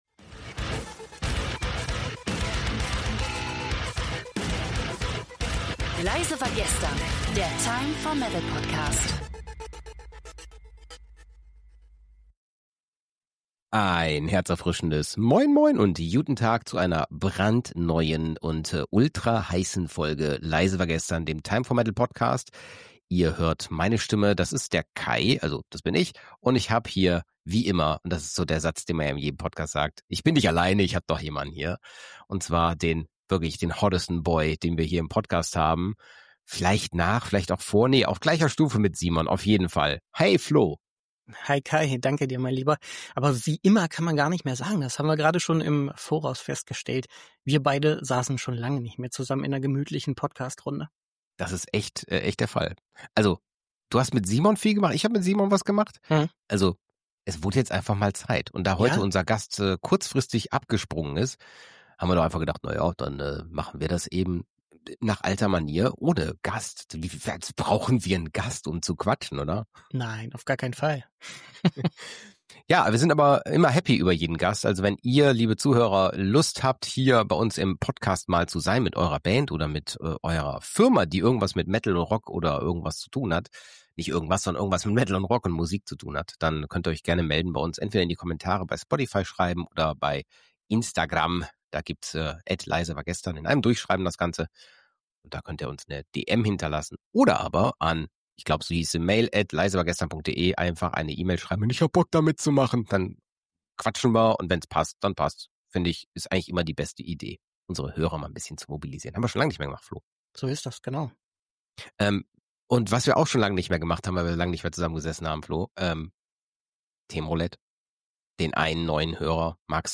Zum Abschluss gibt’s wie immer eine musikalische Perle – diesmal „New Blood“ .